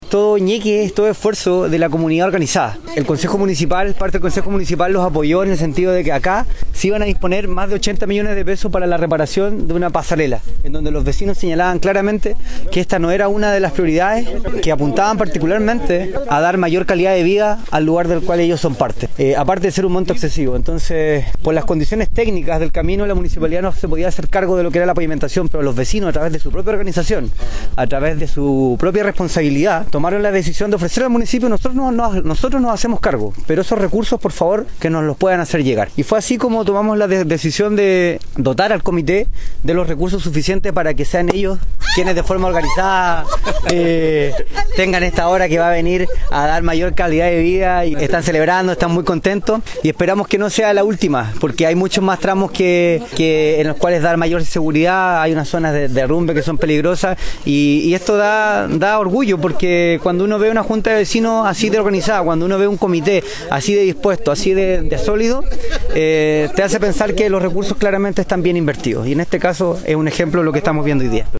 Finalmente, el Concejal Enrique Soto, destacó el compromiso y la responsabilidad de los vecinos para ejecutar este proyecto: